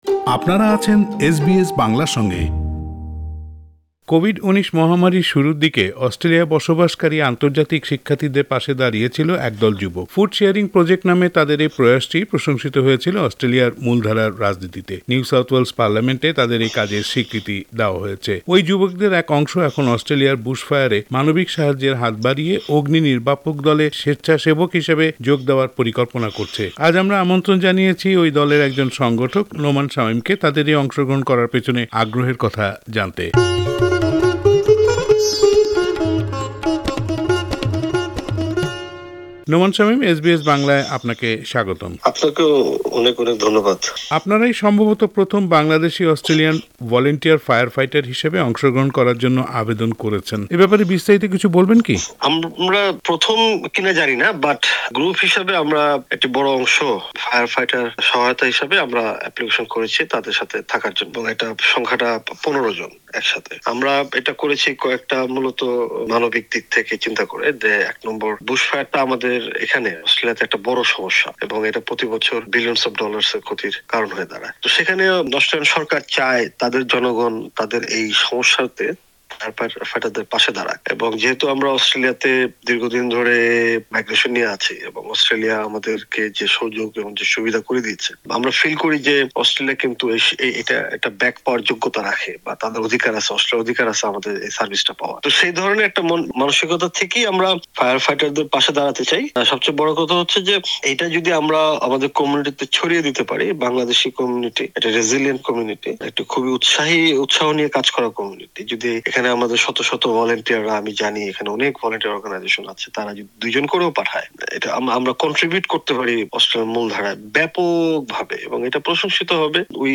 পুরো সাক্ষাৎকারটি বাংলায় শুনতে ওপরের অডিও প্লেয়ারটিতে ক্লিক করুন।